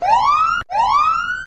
woop.mp3